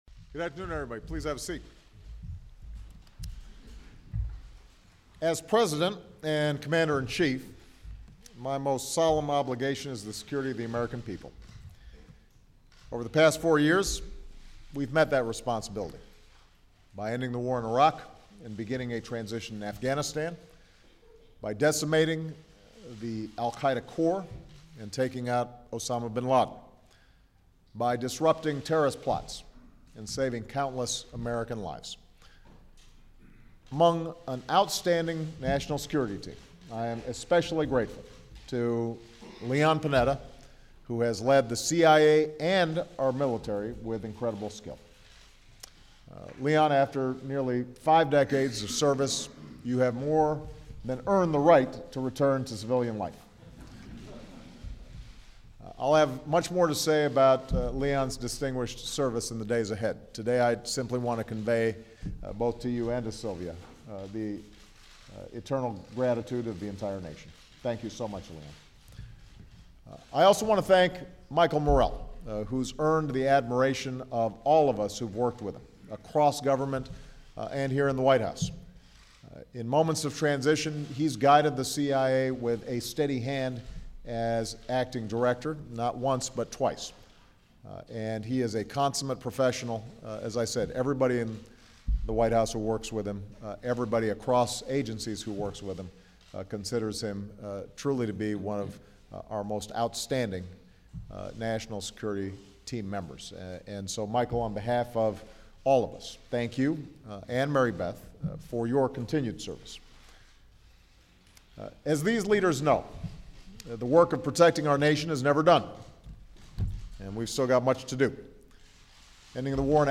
Obama discusses the importance of intelligence and defense in his administration. The current Secretary of Defense, Leon Panetta and the Acting Director of the CIA, Michael Morell speak about the qualifications of the respective nominees.